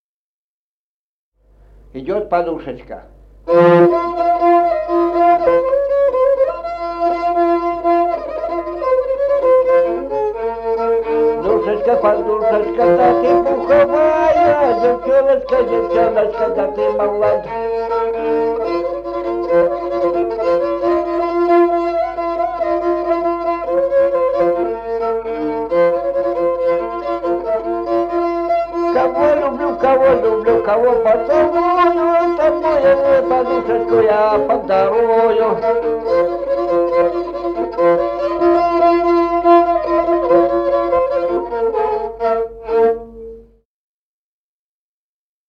Музыкальный фольклор села Мишковка «Подушечка», репертуар скрипача.